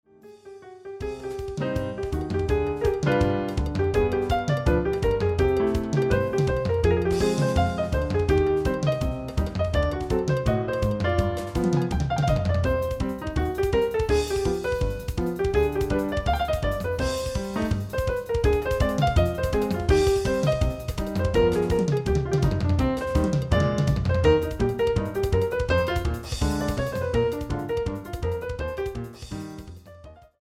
Popular Music from the 1940's
piano,bass and drums